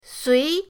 sui2.mp3